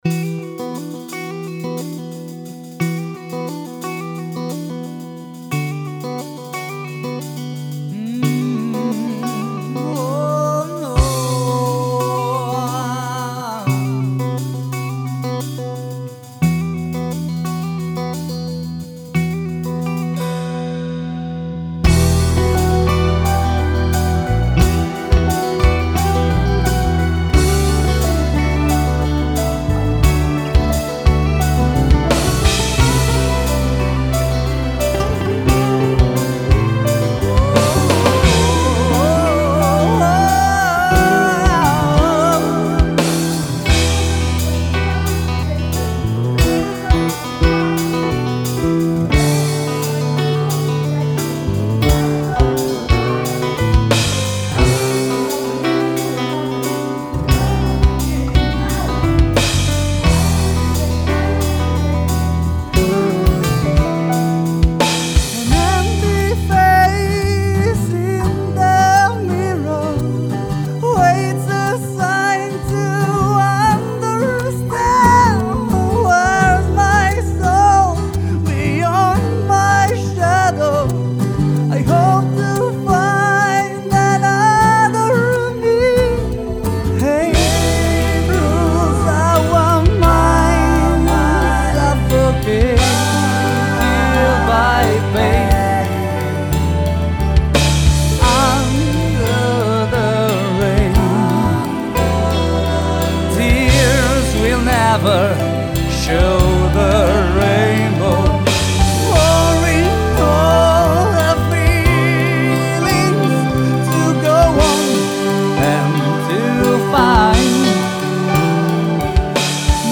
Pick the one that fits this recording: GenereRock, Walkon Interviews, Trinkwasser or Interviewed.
GenereRock